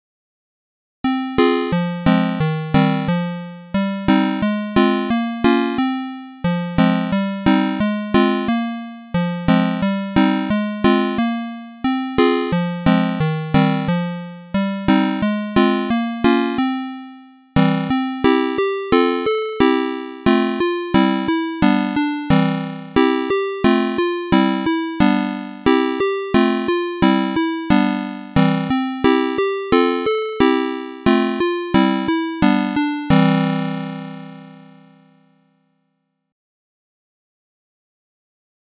Atari-ST Emulation